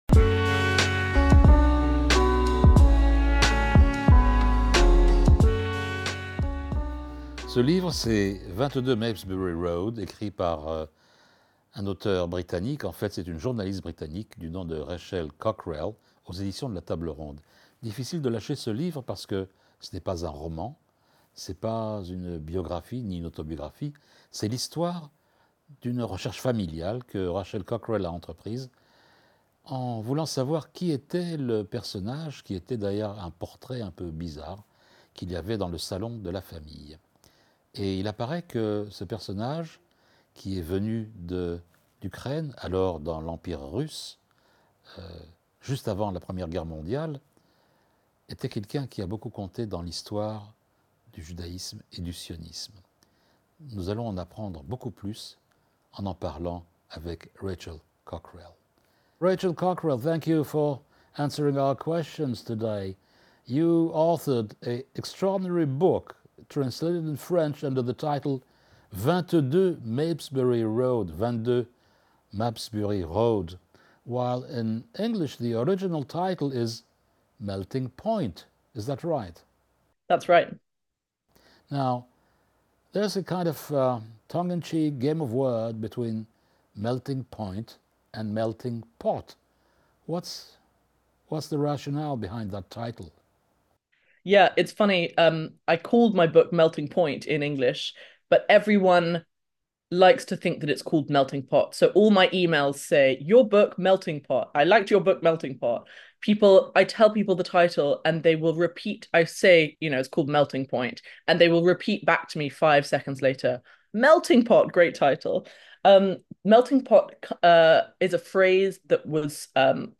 Emission Zangwill